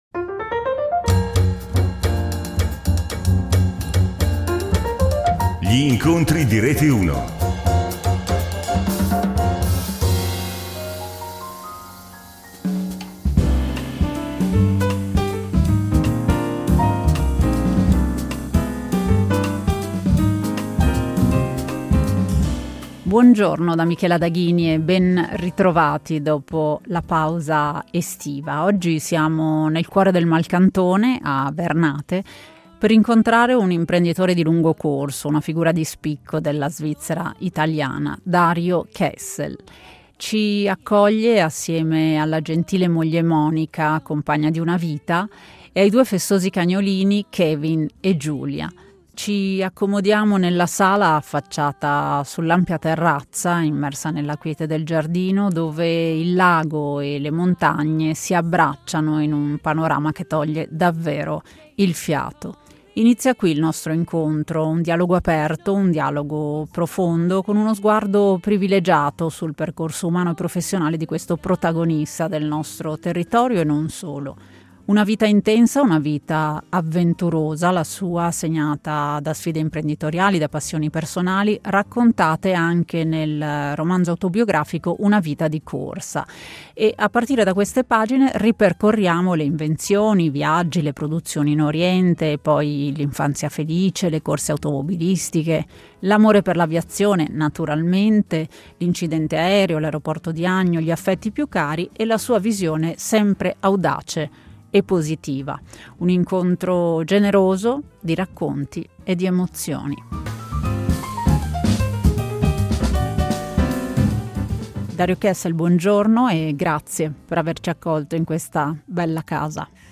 L’intervista si svolge nella sala affacciata sull’ampia terrazza immersa nella quiete del giardino, dove il lago e le montagne si abbracciano in un panorama che toglie il fiato.
Un incontro generoso, di racconti e di emozioni.